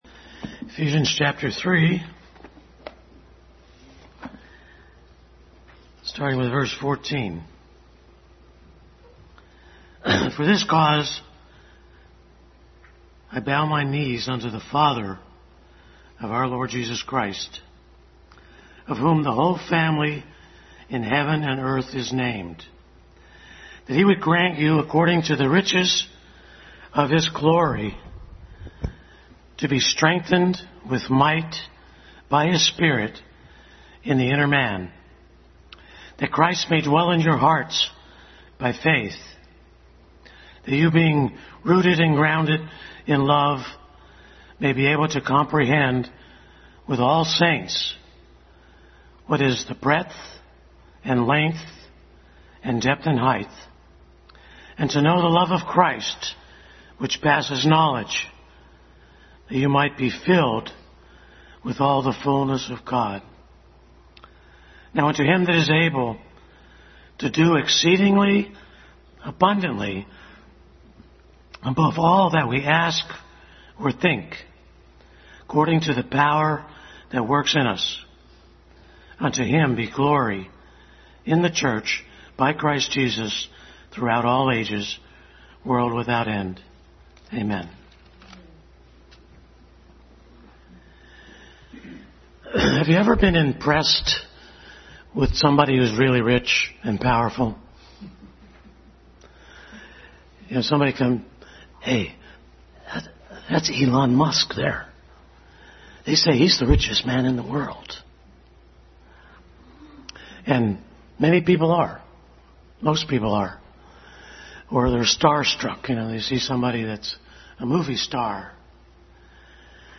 Adult Sunday School continued study in Ephesians.
Ephesians 3:14-21 Service Type: Sunday School Adult Sunday School continued study in Ephesians.